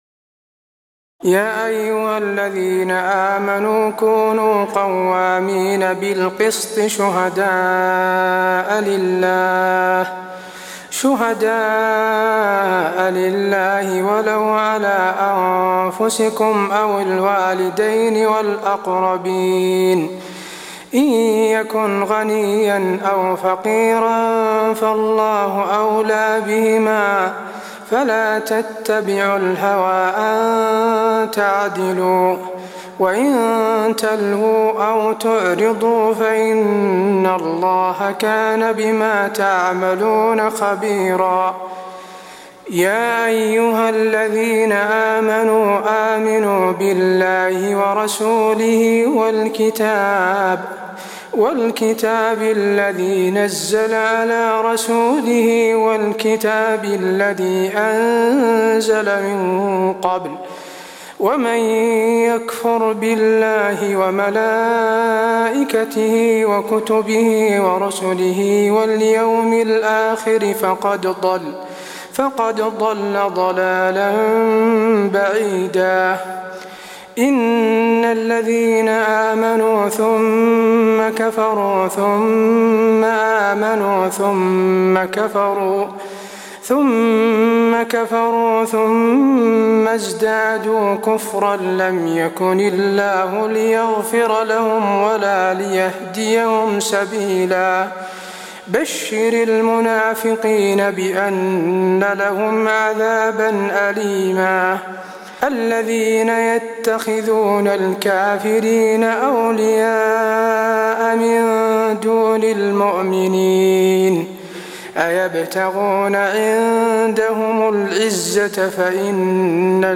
تراويح الليلة الخامسة رمضان 1423هـ من سورة النساء (135-162) Taraweeh 5 st night Ramadan 1423H from Surah An-Nisaa > تراويح الحرم النبوي عام 1423 🕌 > التراويح - تلاوات الحرمين